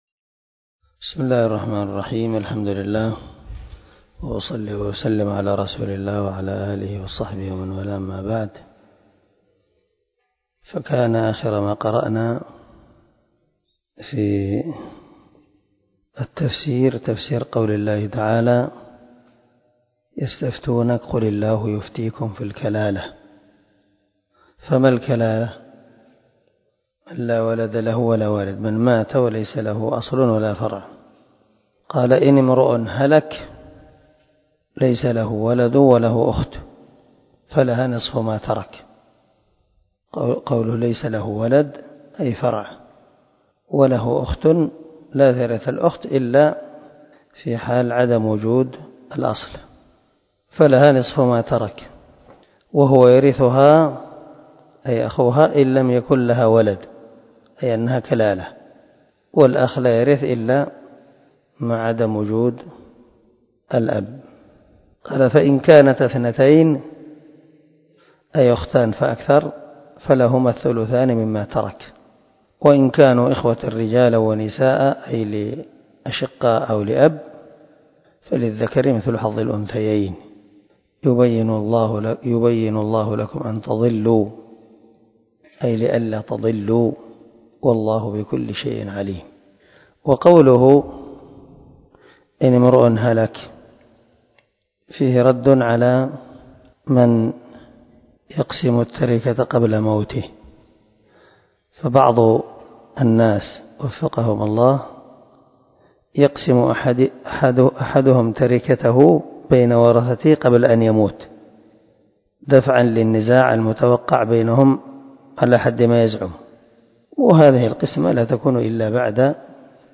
334الدرس 1 تفسير آية ( 1 ) من سورة المائدة من تفسير القران الكريم مع قراءة لتفسير السعدي